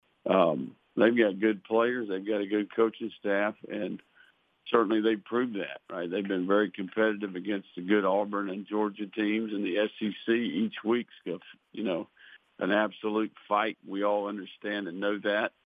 Florida head football coach Billy Napier addressed the media on Wednesday’s SEC teleconference to preview the matchup.